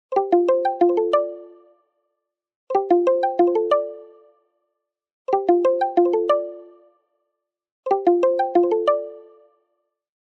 18. kalimbalert